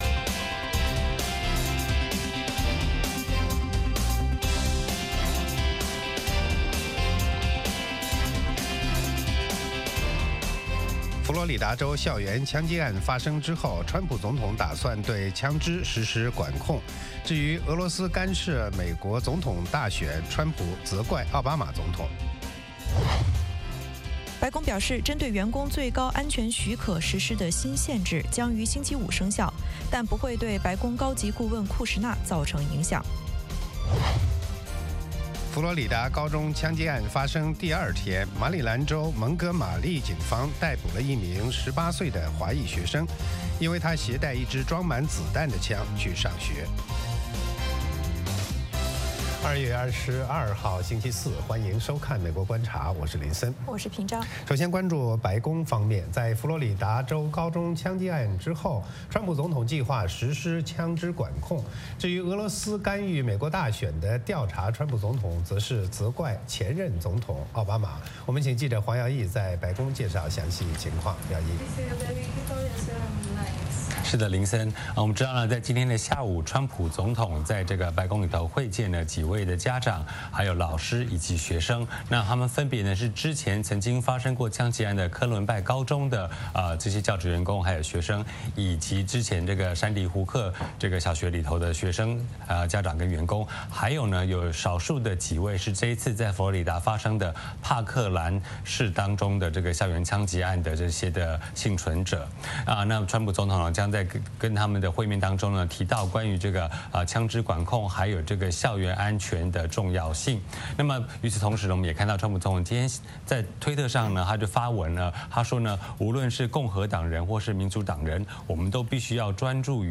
美国之音中文广播于北京时间早上8－9点重播《VOA卫视》节目(电视、广播同步播出)。
“VOA卫视 美国观察”掌握美国最重要的消息，深入解读美国选举，政治，经济，外交，人文，美中关系等全方位话题。节目邀请重量级嘉宾参与讨论。